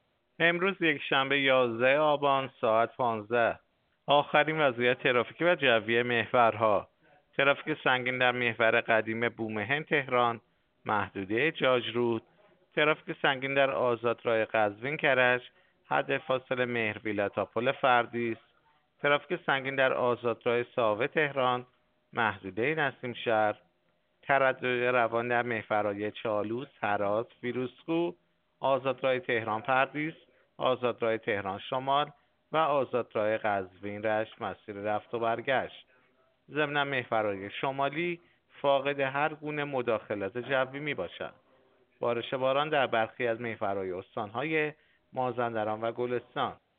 گزارش رادیو اینترنتی از آخرین وضعیت ترافیکی جاده‌ها ساعت ۱۵ یازدهم آبان؛